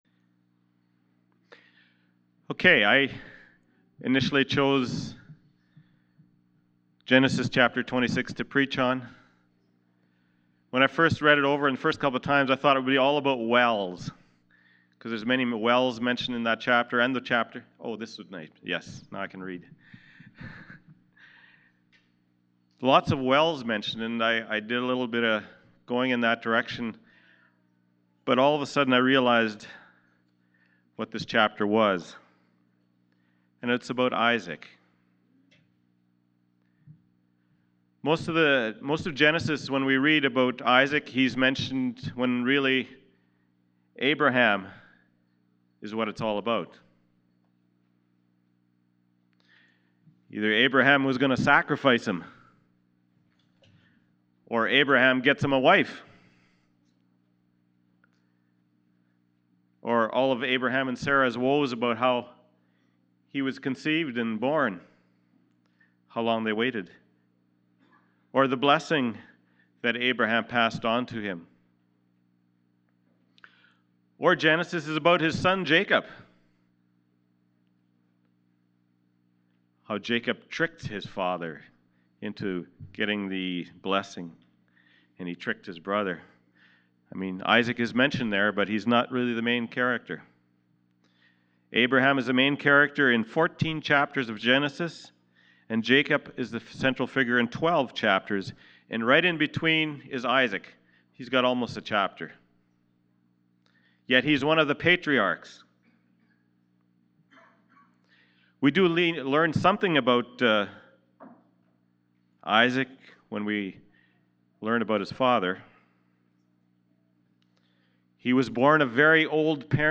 Passage: Genesis 26:1-17 Service Type: Sunday Morning « Morning and Evening Prayer Ecclesiastes